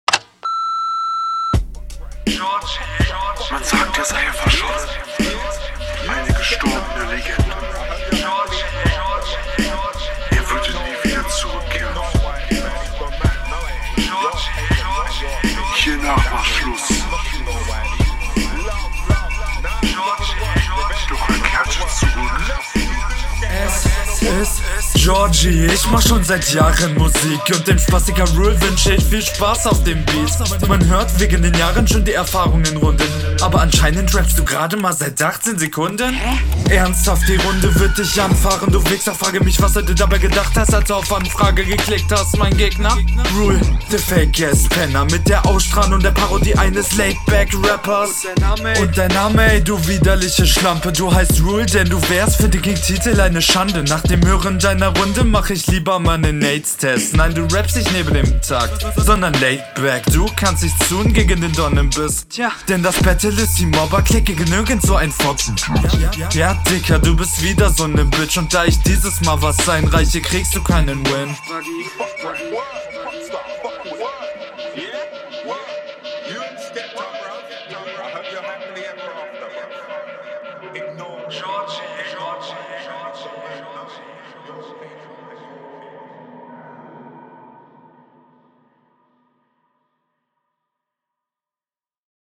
Flow: Er flowt extrem gut auf dem Beat und auch seine Delivery ist sehr gut.
Flow: Gut gerappt Text: Textlich wie solide, etwas schlechter als deine RR Soundqualität: Coole mische, …